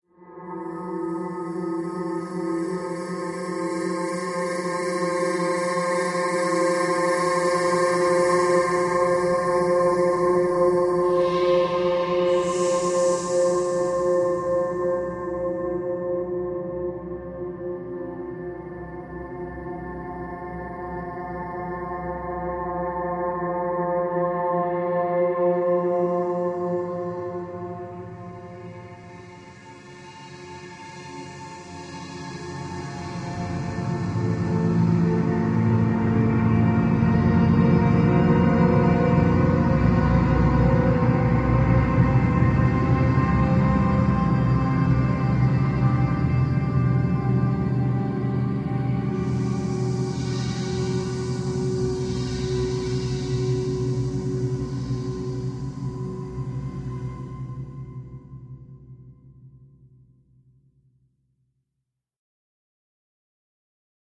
Mystic Whirling Tones with Crescendo At End Mystic, Whirling